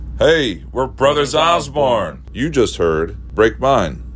LINER Brothers Osborne (Break Mine) 6